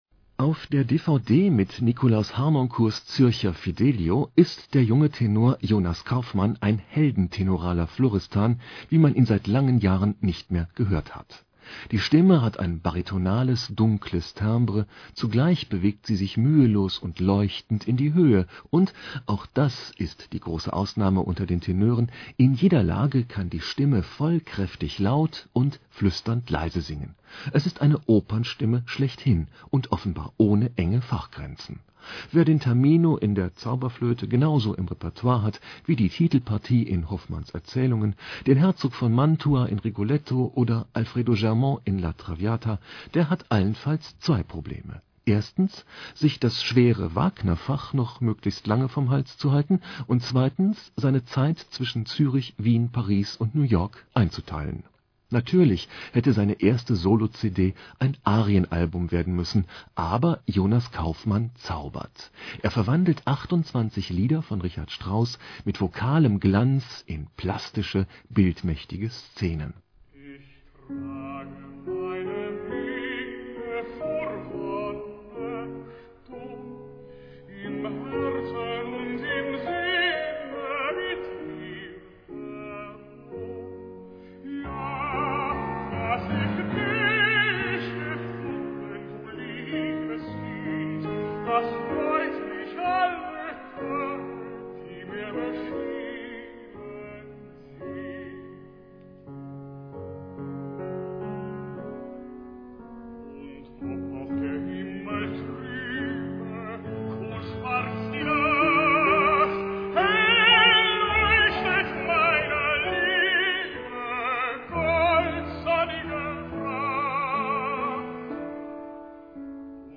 Audiorezension, WDR 3, Hörzeichen, neue CDs ( in German )
Jonas Kaufmann ist der Geheimtipp unter den Tenören. Die Stimme hat ein baritonales, dunkles Timbre, zugleich bewegt sie sich mühelos und leuchtend in die Höhe, und - auch das die große Ausnahme unter den Tenören: in jeder Lage kann Kaufmann vollkräftig laut und flüsternd leise singen.